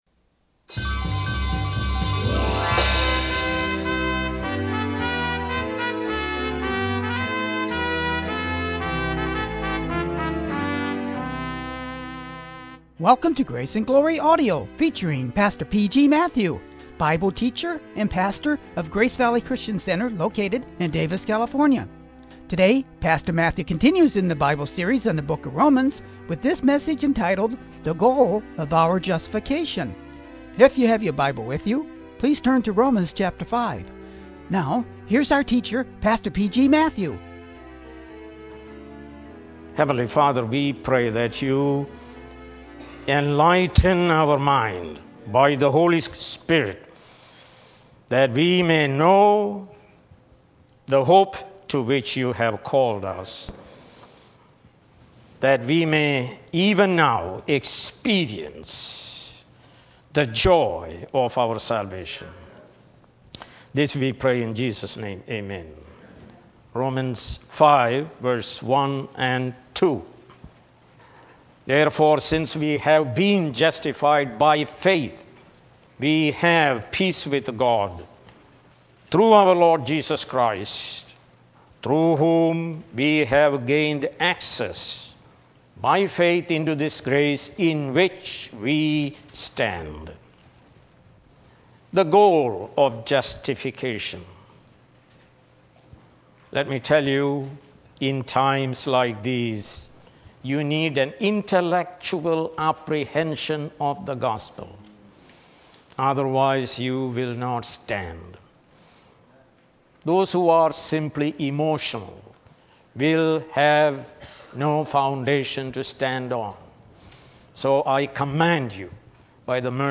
Sermons | Grace Valley Christian Center